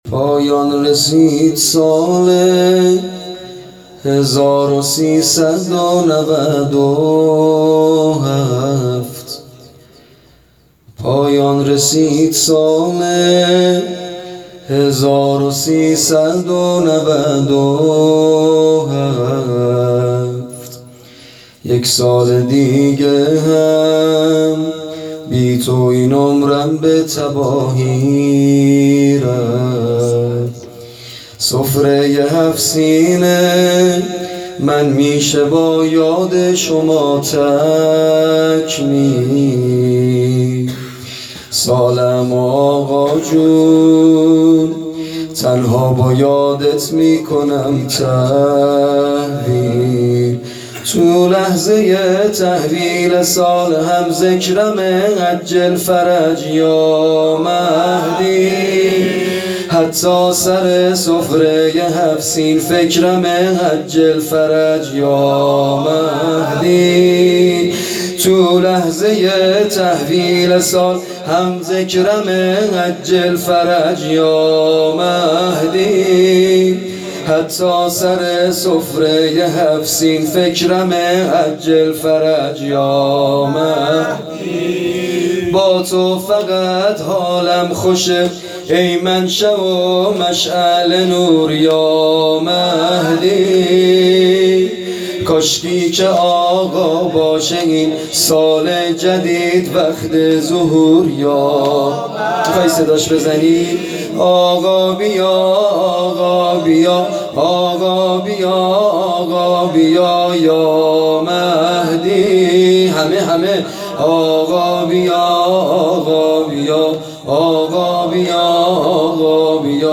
میلاد امیرالمومنین علی علیه السلام۹۷